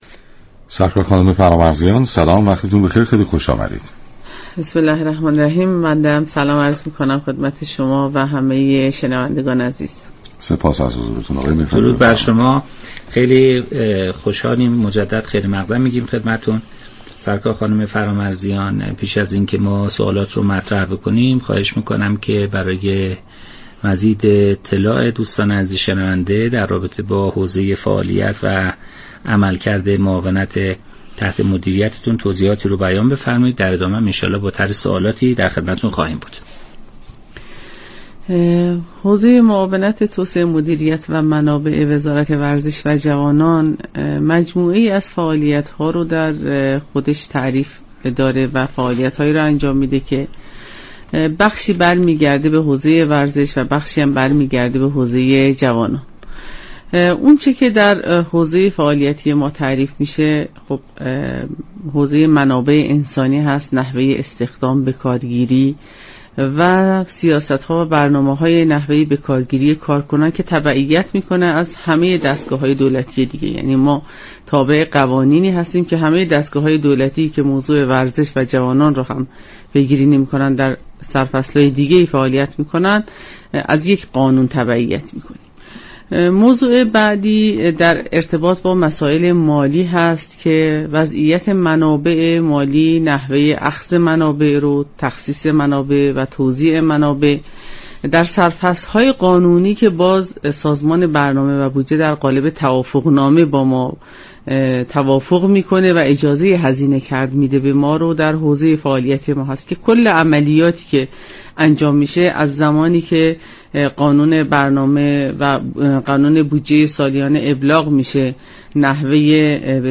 شما می توانید از طریق فایل صوتی پیوست شنونده این گفتگو باشید.